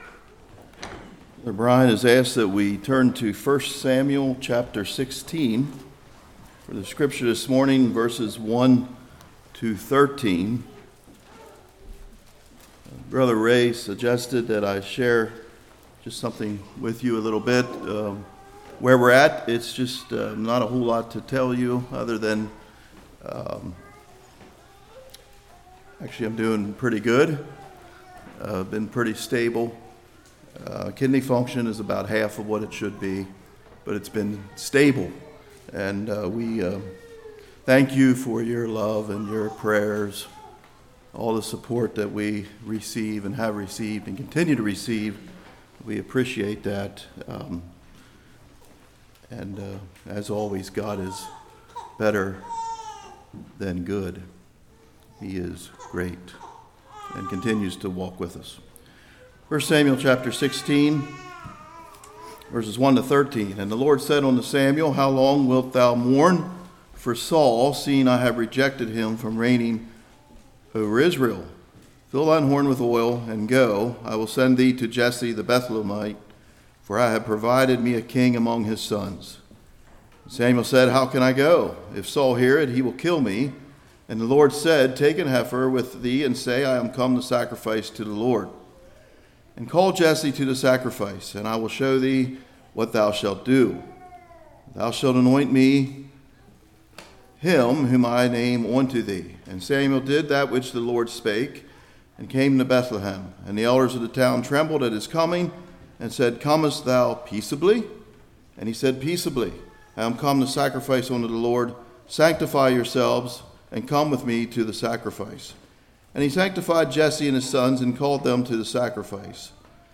1 Samuel 16:1-13 Service Type: Morning Prayer